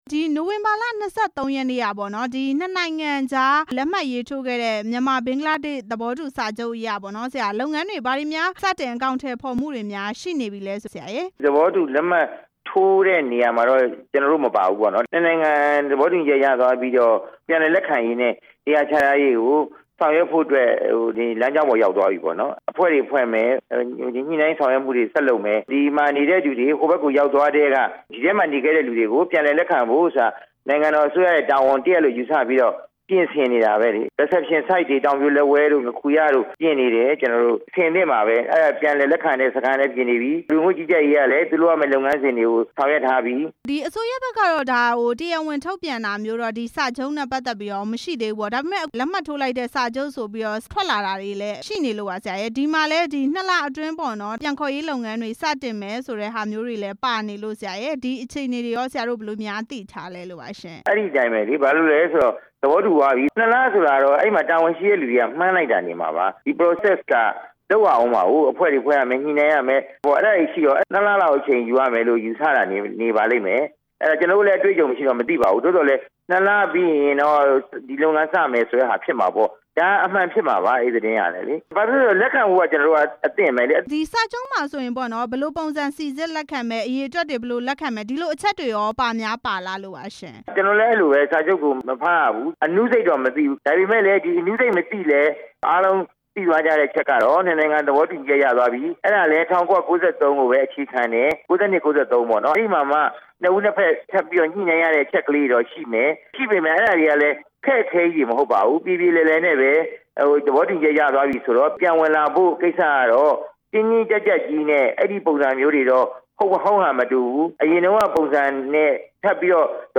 ဒုက္ခသည် ပြန်လည်လက်ခံရေး ဝန်ကြီး ဒေါက်တာ ဝင်းမြတ်အေး နဲ့ မေးမြန်းချက်